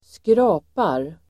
Uttal: [²skr'a:par]